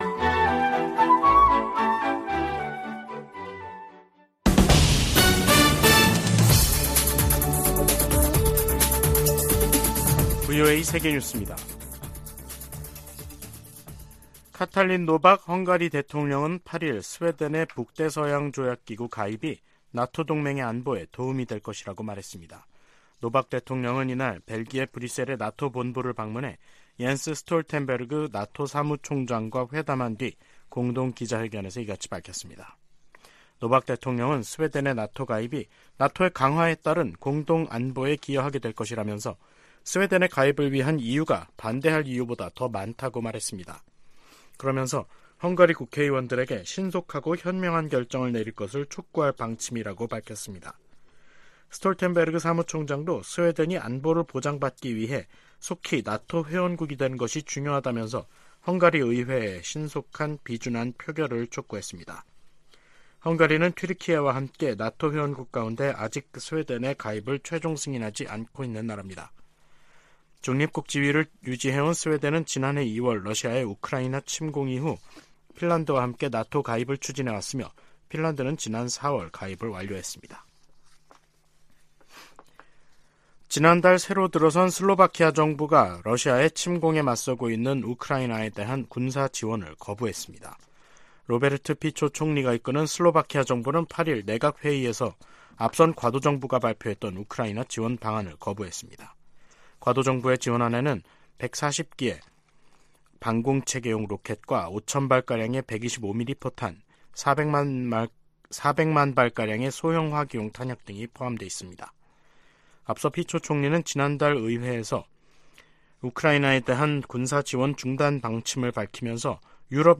VOA 한국어 간판 뉴스 프로그램 '뉴스 투데이', 2023년 11월 9일 3부 방송입니다. 토니 블링컨 미 국무장관과 박진 한국 외교장관이 9일 서울에서 만나 북러 군사협력 문제 등 현안을 논의했습니다. 백악관은 미국이 한반도 비상상황 대비를 위해 늘 노력하고 있다며, 한국의 중동 개입은 '주권적 결정' 사안이라고 강조했습니다. 주요7개국(G7) 외교장관들이 북한의 지속적인 대량살상무기 개발과 러시아로의 무기 이전을 강력히 규탄했습니다.